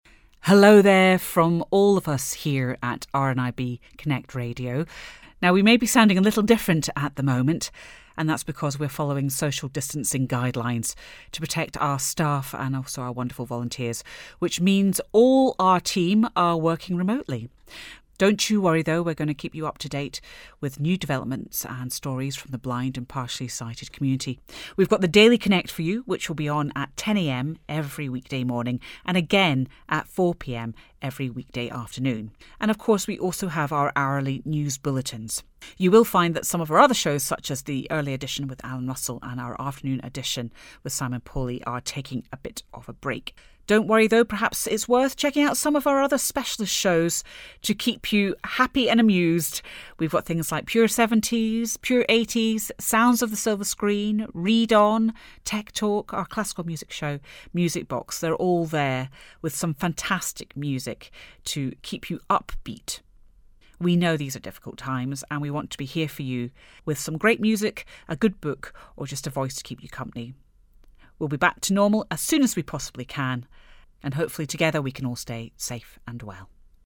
We may sound a little different at the moment, that's because we're following social distancing guidelines to protect our staff and wonderful volunteers which means all the team are working remotely.